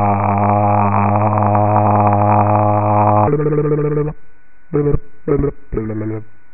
Jsem příjemně překvapen kvalitou nahrávek, obzvlášť vzhledem k tomu, že vzorkovací frekvence €Labu je jen 5 kHz (což je opravdu málo).
(recording.wav - WAV nahrávka "ááááááá" a "blllblbllbl", upsamplováno na 44.1 kHz, mono, 16 bitů, ručně zesíleno)